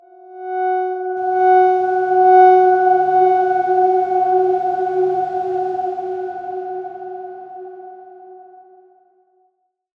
X_Darkswarm-F#4-pp.wav